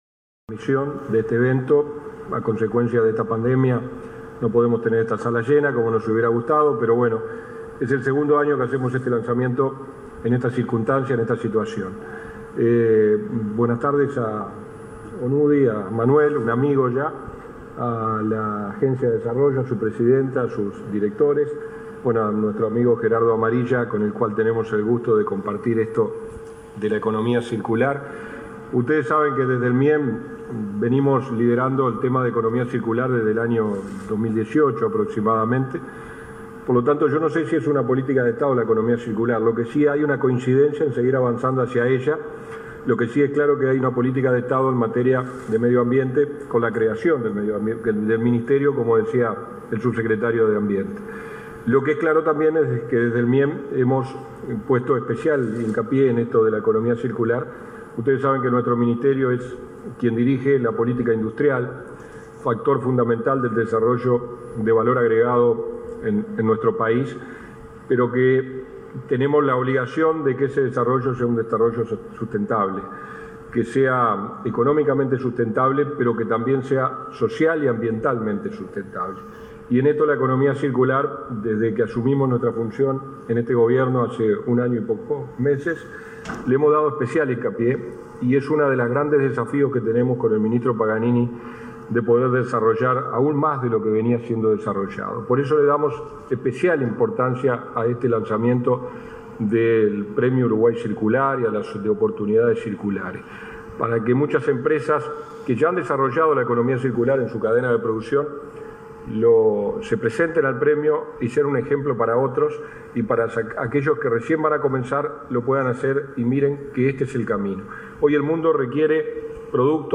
Palabras de los subsecretarios de Industria, Walter Verri, y de Ambiente, Gerardo Amarilla, y de la presidenta de ANDE, Carmen Sánchez